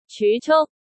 cyu5-cuk1.mp3